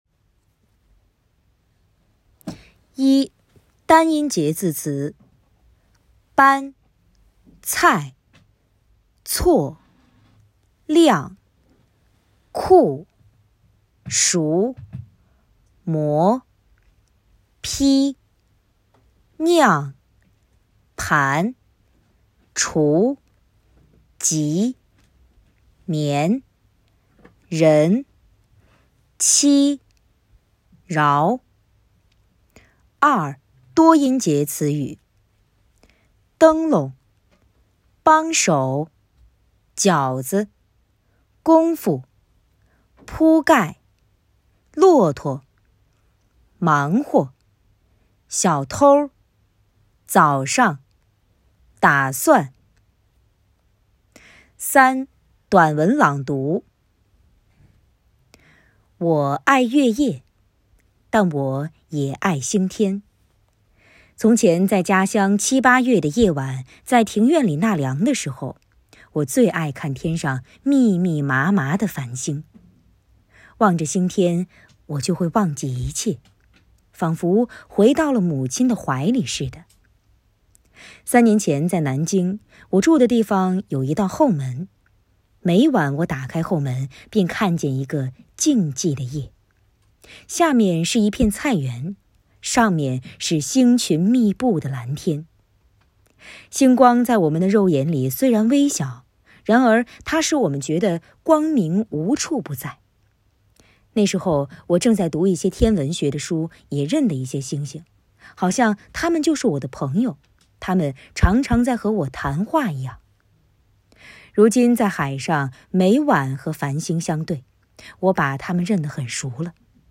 领读课件
（领读音频于文末，可播放跟读练习）